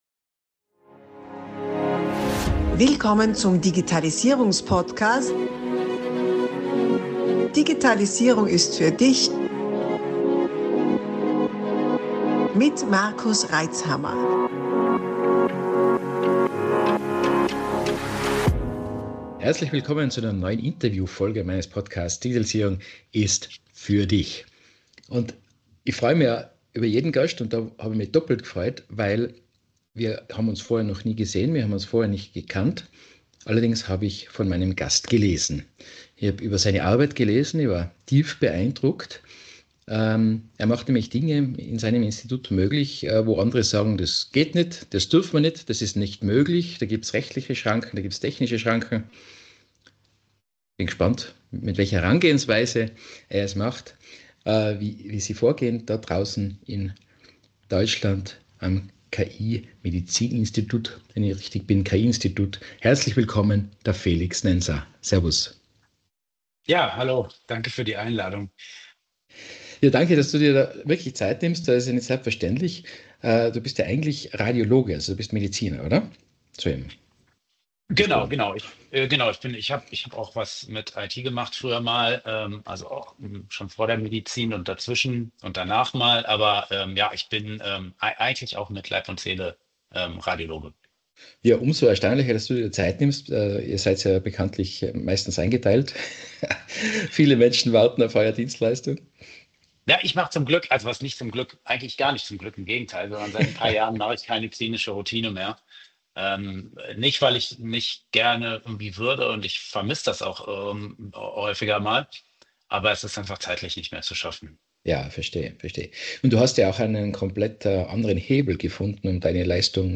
Er ist nicht nur praktizierender Radiologe und Mediziner, sondern auch ein versierter IT-Experte.